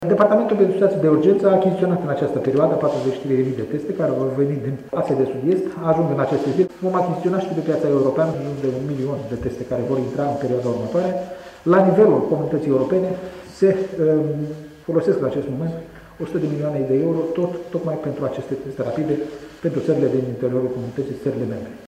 Aproape un milion și jumătate de astfel de teste rapide ar urma să ajungă în ţară în perioada următoare, spune ministrul Sănătăţii, Nelu Tătaru: